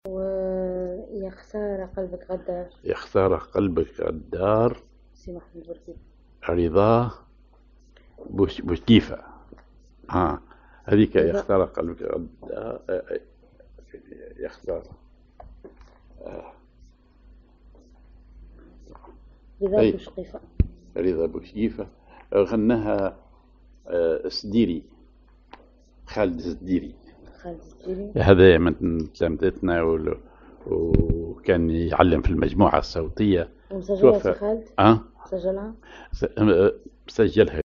ar حجاز على درجة النوا
أغنية